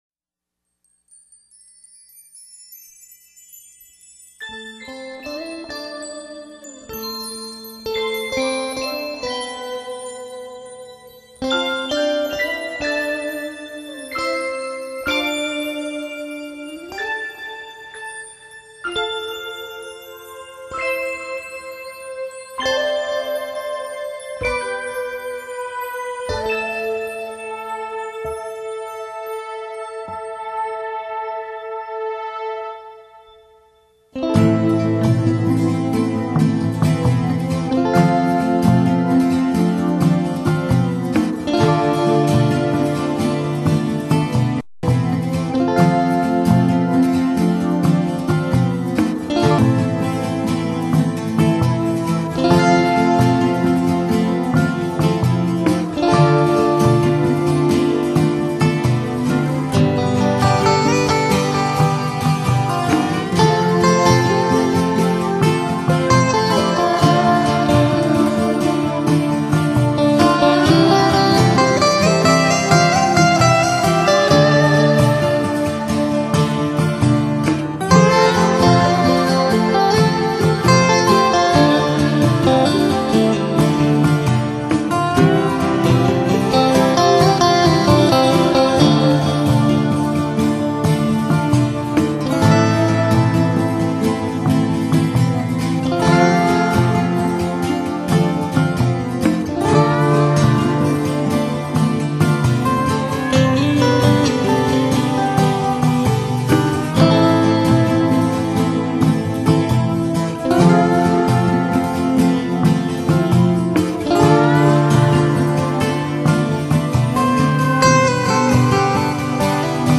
风格：New Flamenco
本片结合吉普赛人流浪的情结，加上东方的迷幻多情，使整张专辑营造出一幕幕幽远意象，如泣如诉地演奏出感人动听的旋律。